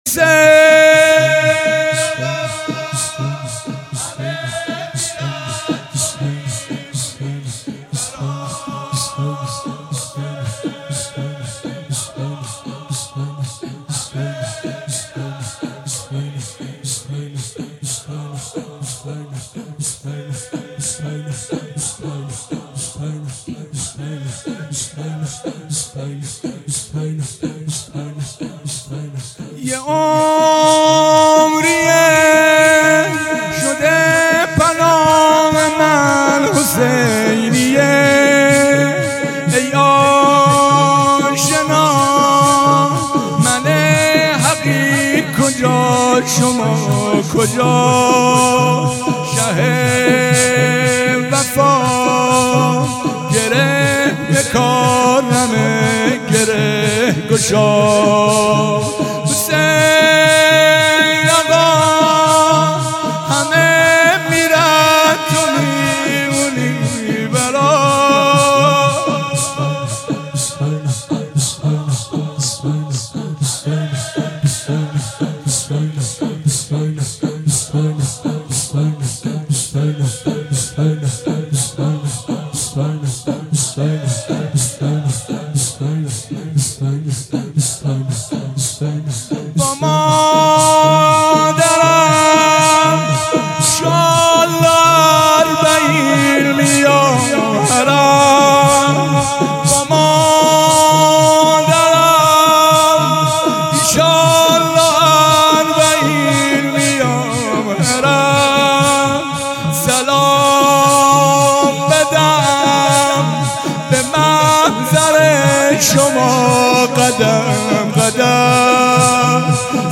هیئت متوسلین به اهل بیت (ع) شمیران
شور
دهه اول مــحـرمالـحـــرام ۱۴۴۱